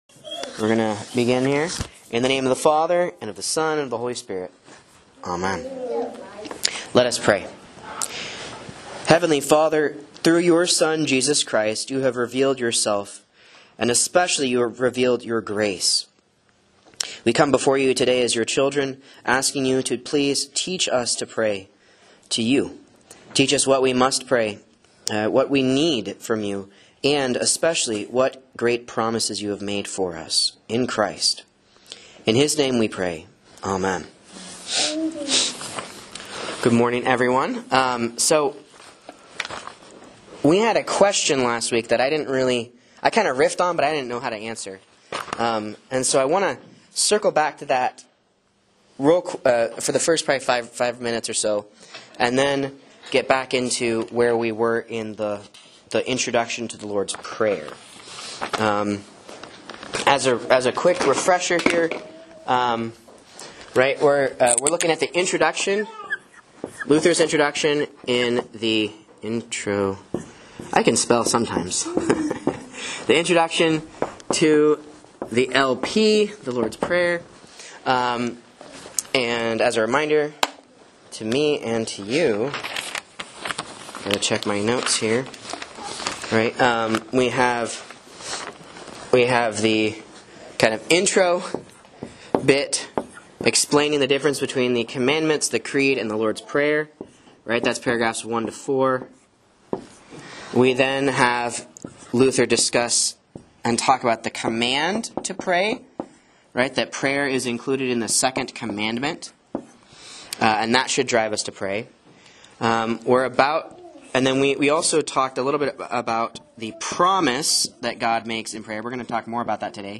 Sermons and Bible Classes